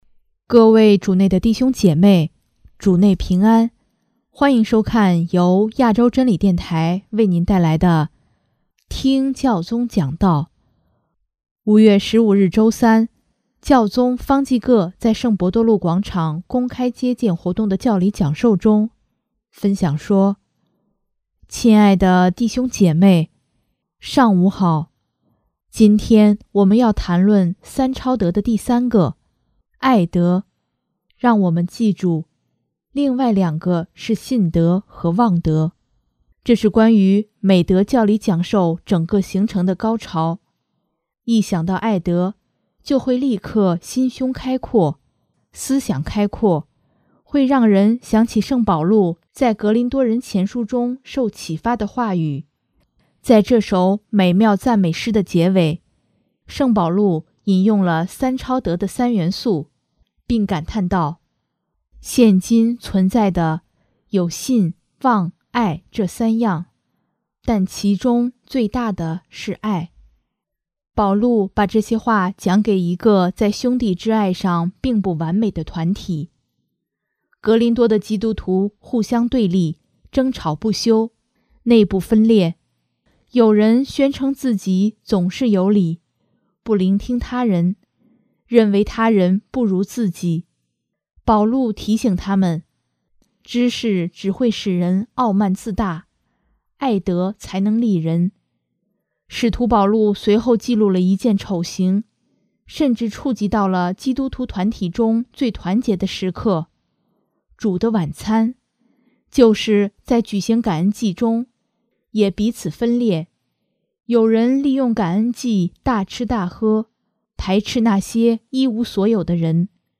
5月15日周三，教宗方济各在圣伯多禄广场公开接见活动的教理讲授中，分享说：